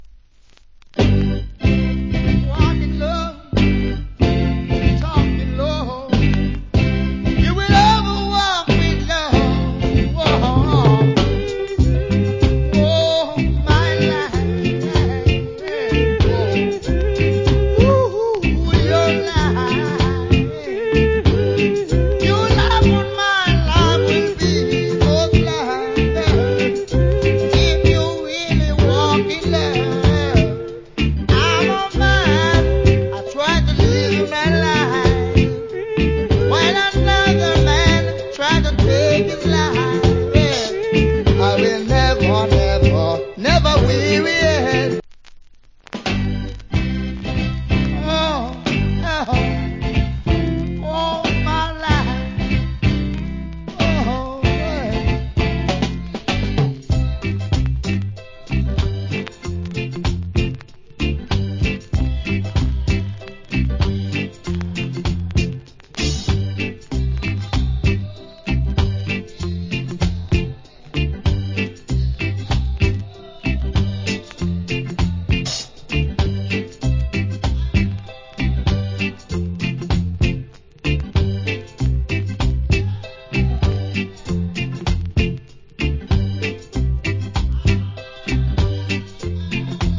コメント Nice Reggae Vocal.